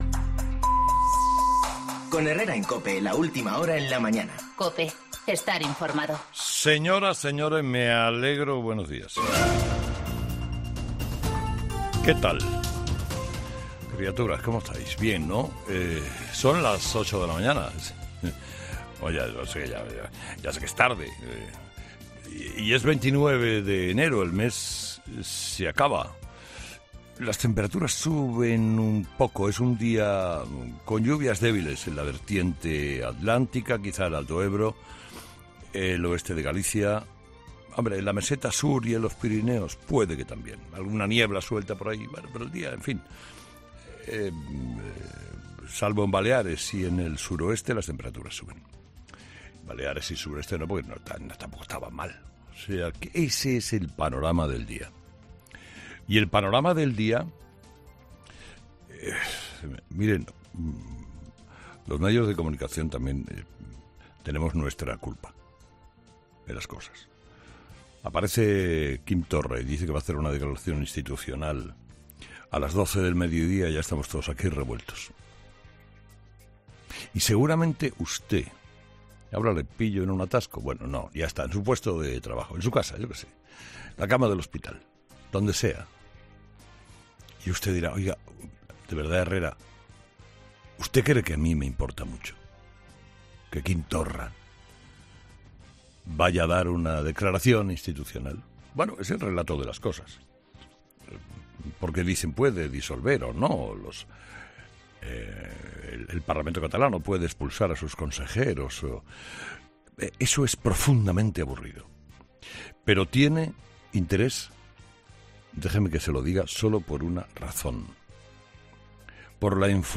Monólogo de las 8 de Herrera
El director y presentador mejor valorado de la radio española, Carlos Herrera, ha comenzado hoy en 'Herrera en COPE' explicando cuál es la importancia que tienen los soberanistas en el nuevo puzle político.